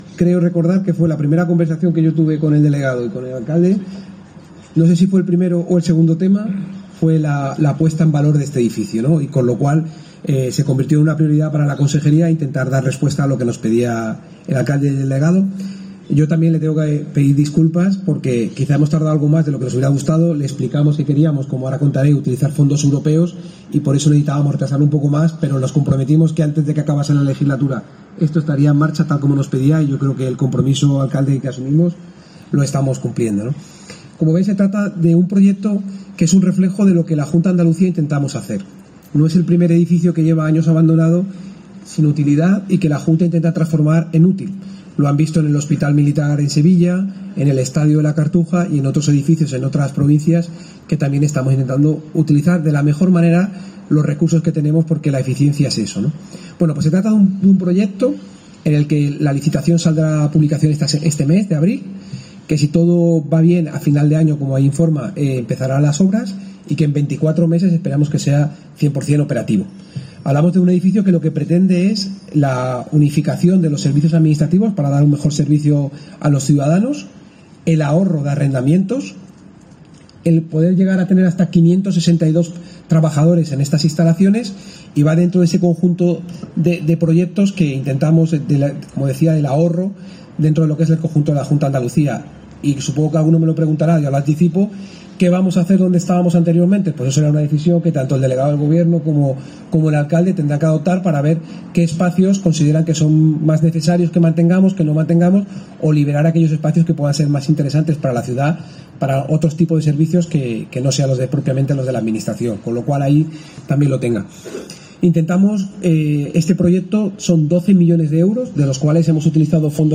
Escucha a Juan Bravo, consejero de Hacienda en la Junta de Andalucía